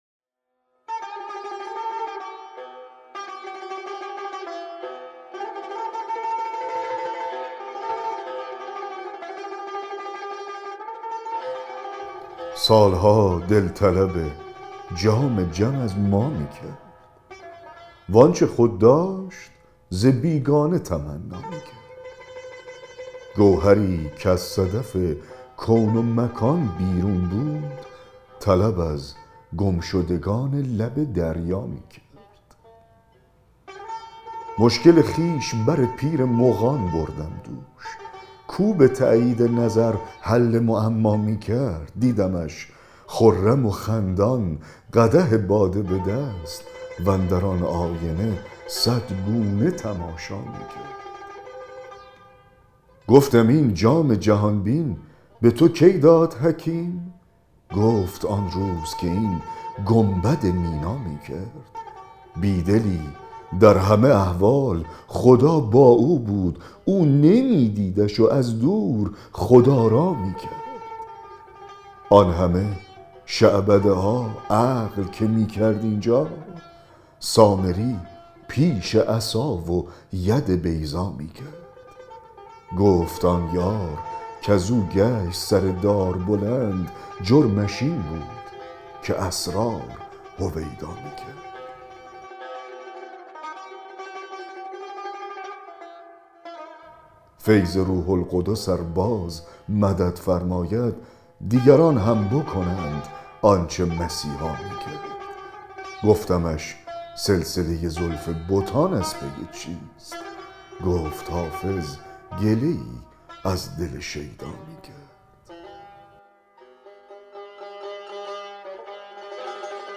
دکلمه غزل 143 حافظ